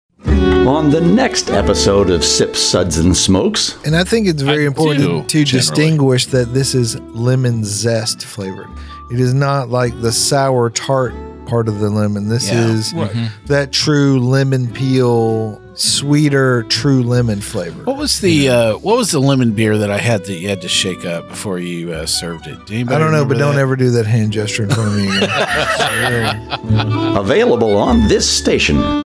192kbps Mono